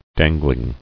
[dan·gling]